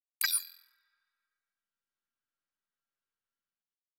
Futurisitc UI Sound 11.wav